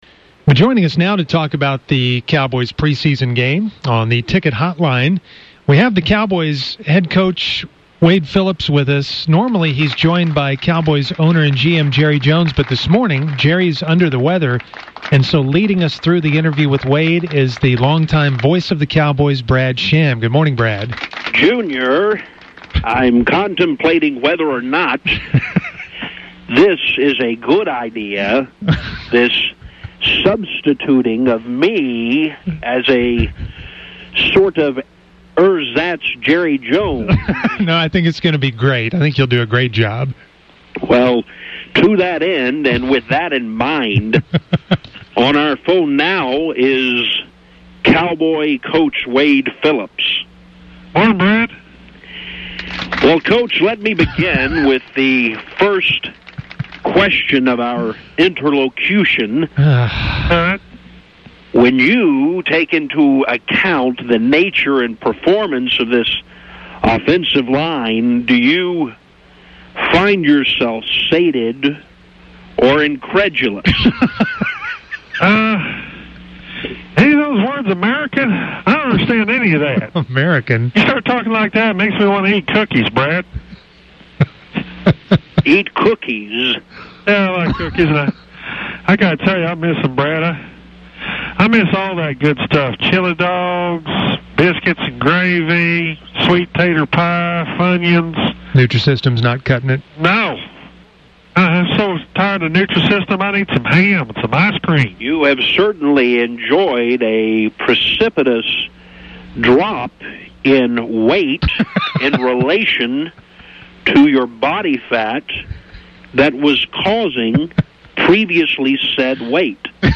After the pre-season win against the San Diego Chargers, the boys were supposed to have fake Jerry & Wade on but Jerry was busy. Instead, the extremely fake Brad Sham joined fake Wade to discuss the game.
Unfortunately, or fortunately…whichever side of the fence you fall on, fake Brad Sham took over the interview.